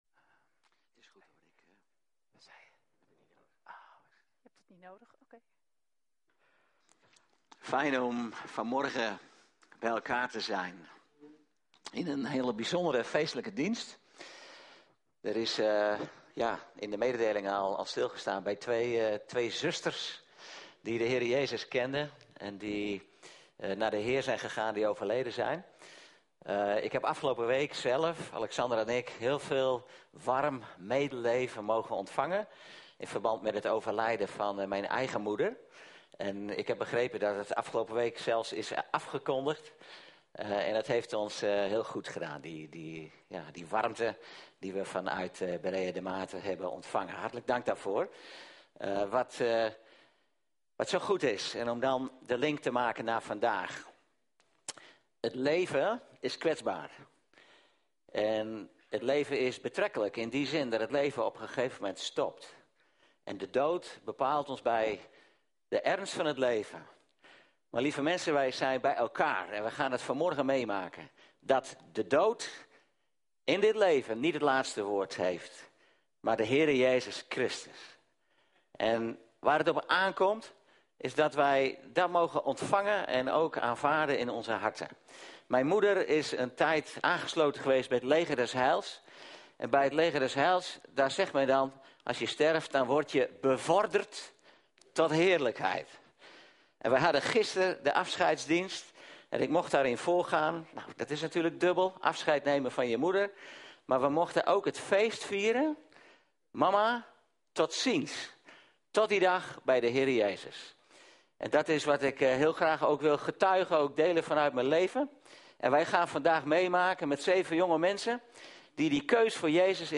Doopdienst